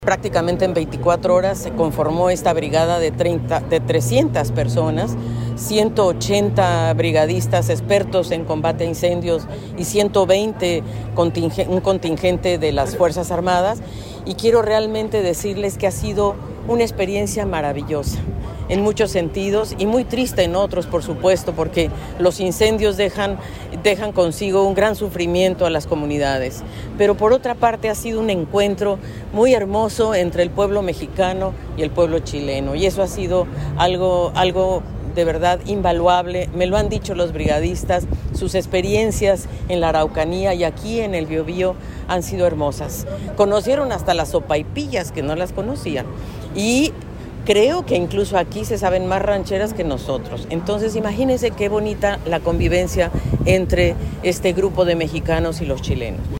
La Plaza Independencia, en el centro de Concepción, fue el escenario de la ceremonia en la que autoridades regionales despidieron a los brigadistas mexicanos y españoles que, durante cuatro semanas, participaron del combate de los incendios forestales en la zona centro sur.